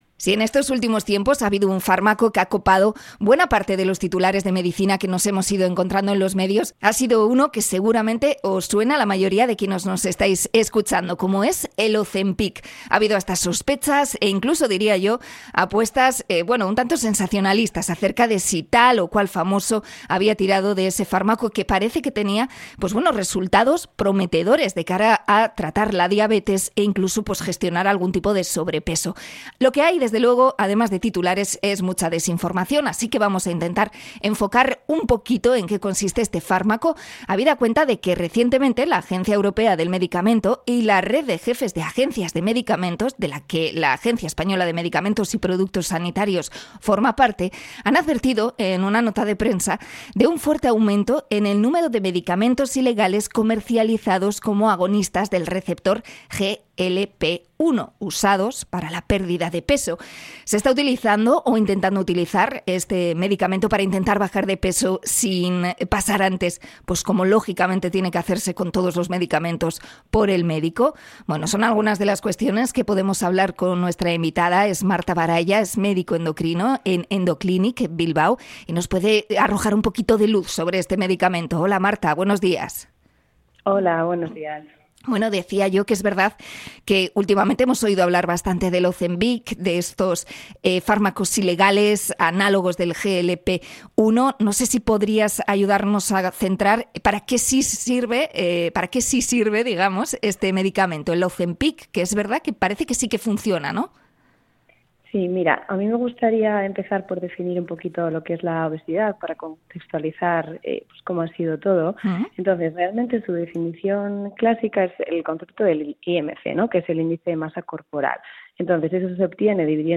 Entrevista a endocrina por la advertencia de la red europea del medicamento sobre el Ozempic